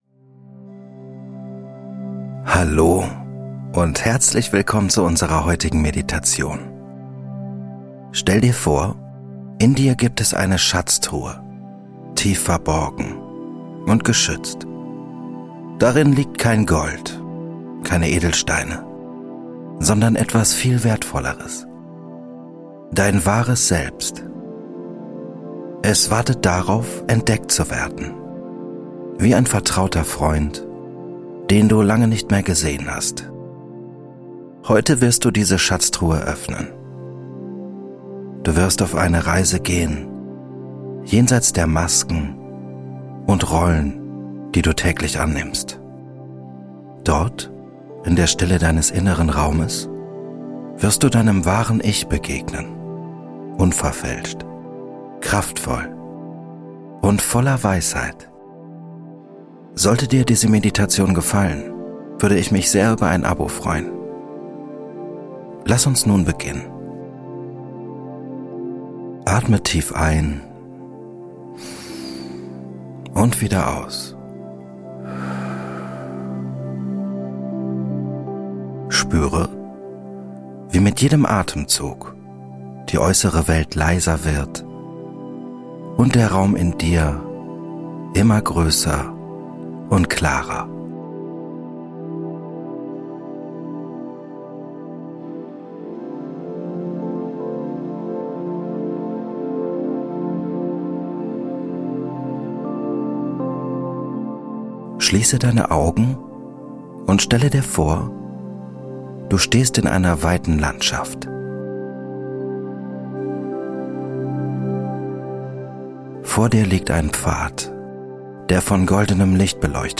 Geführte Meditation - Begegne deinem wahren Selbst ~ Kopfkanal - Geführte Meditationen Podcast
In dieser geführten Meditation wirst du sanft in einen Zustand der Ruhe begleitet, der es dir ermöglicht, den Kontakt zu deinem wahren Selbst herzustellen.